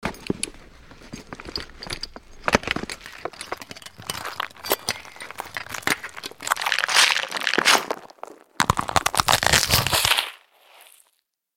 Surgery
Surgery_Sound.mp3